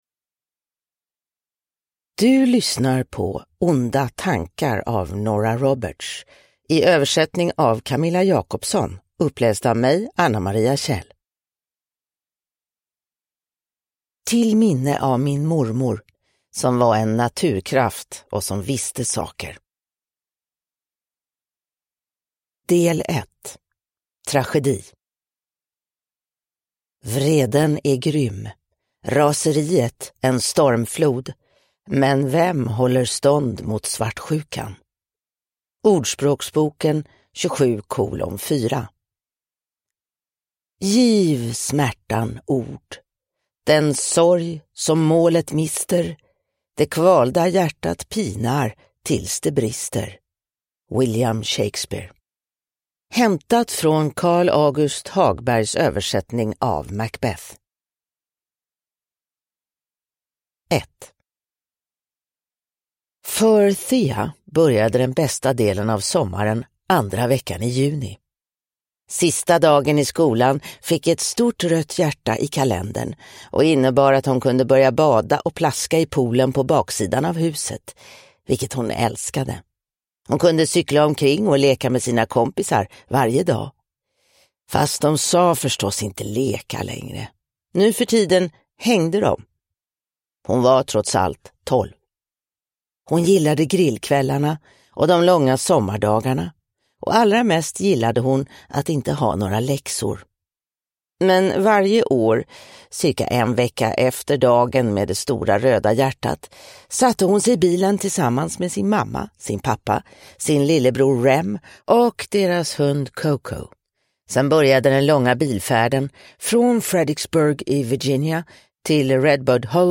Onda tankar (ljudbok) av Nora Roberts